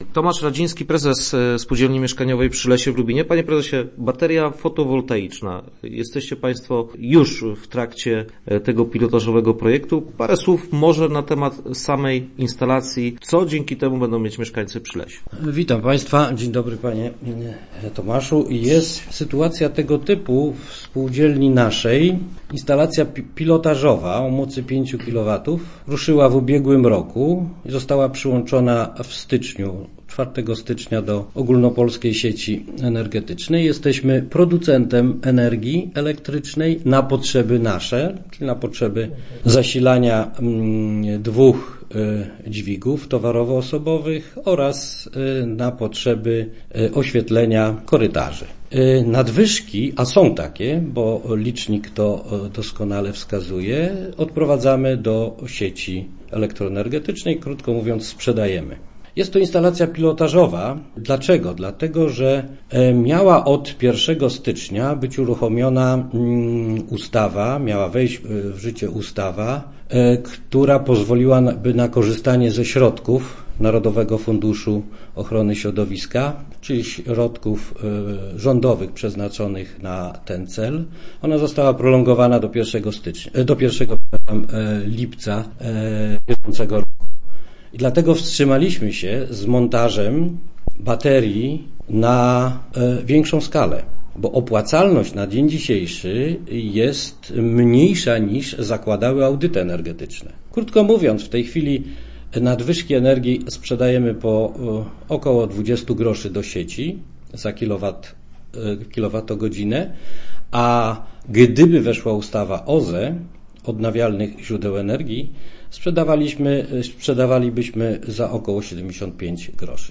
Start arrow Rozmowy Elki arrow Baterie słoneczne na blokach